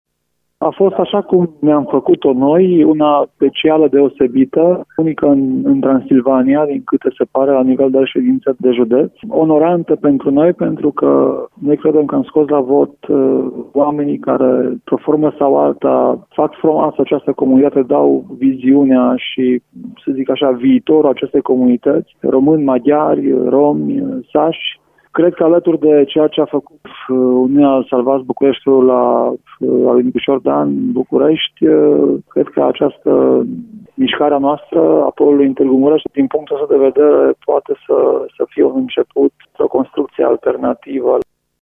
După încheierea scrutinului el a declarat pentru reporterul Radio Tg.Mureș că experiența acestui scrutin a fost unică: